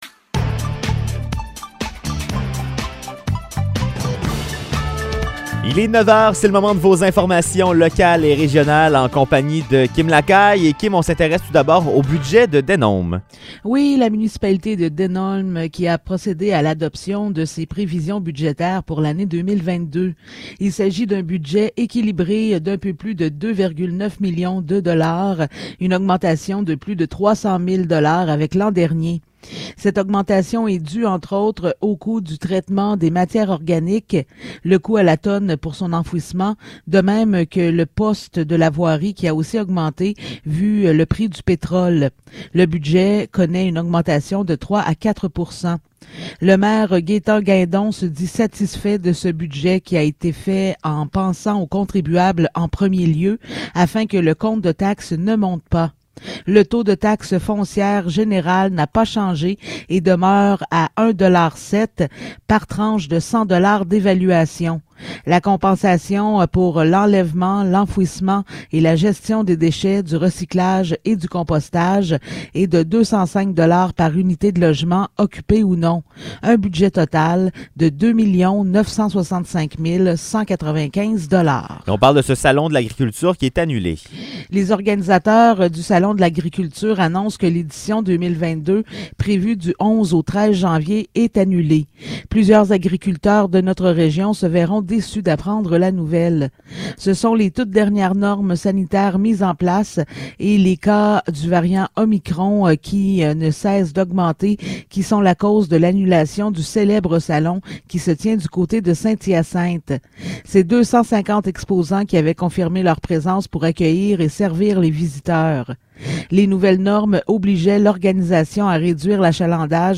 Nouvelles locales - 30 décembre 2021 - 9 h